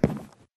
wood3.ogg